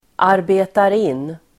Uttal: [arbe:tar'in:]